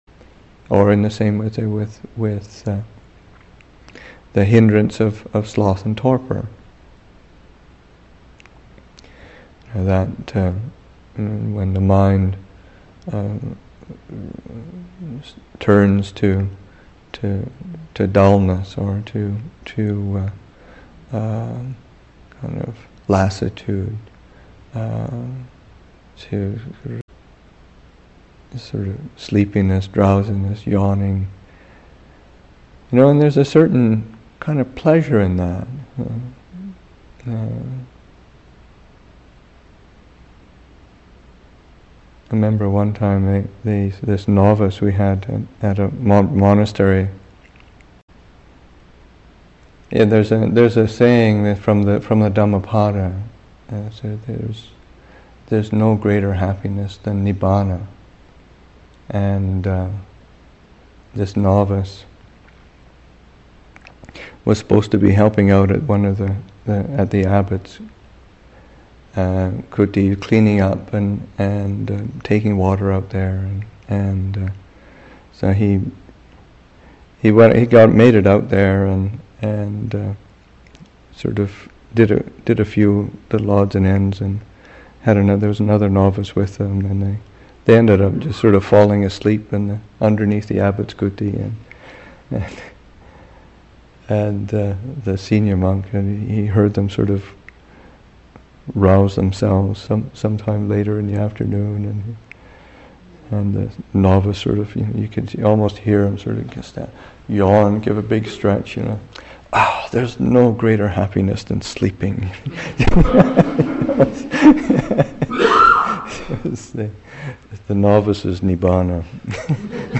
Abhayagiri Buddhist Monastery in Redwood Valley, California